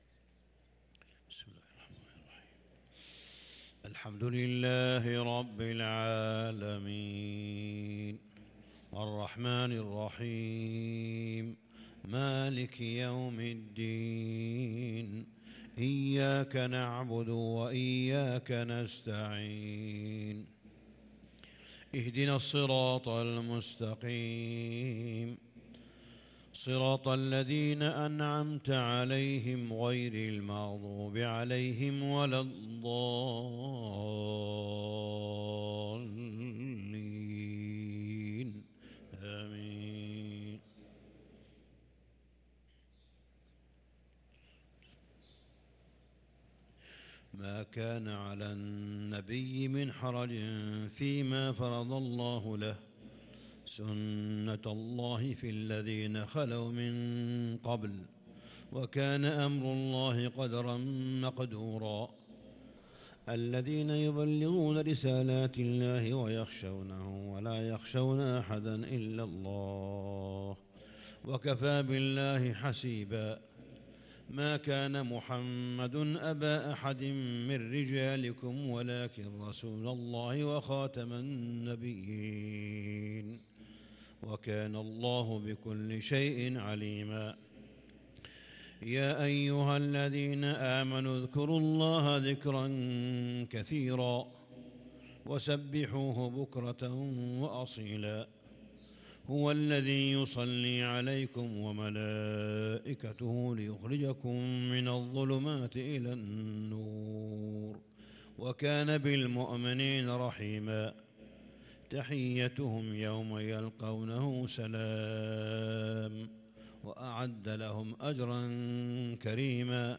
صلاة الفجر للقارئ صالح بن حميد 21 رمضان 1443 هـ